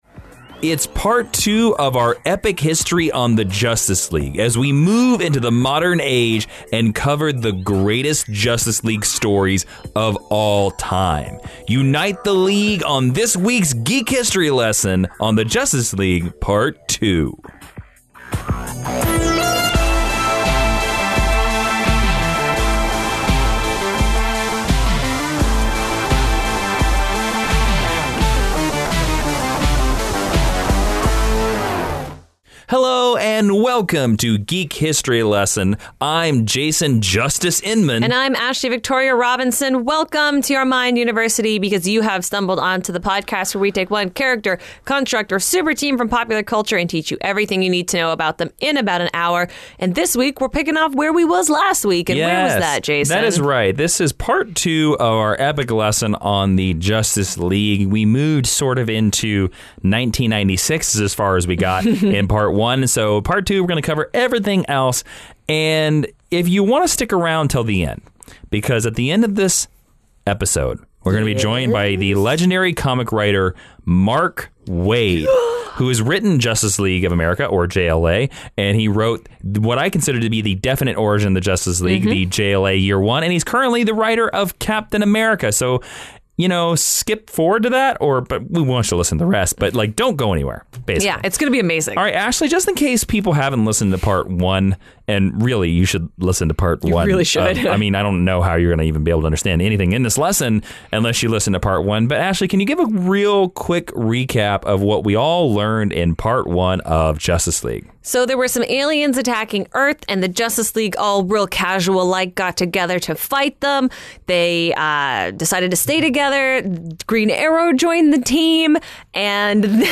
Downloads Download GHL_187_-_Justice_League_Part_Two.mp3 Download GHL_187_-_Justice_League_Part_Two.mp3 Content Follow the Justice League into their modern adventures and then join us for an interview with JLA Year One writer Mark Waid!